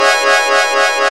1805L SYNRIF.wav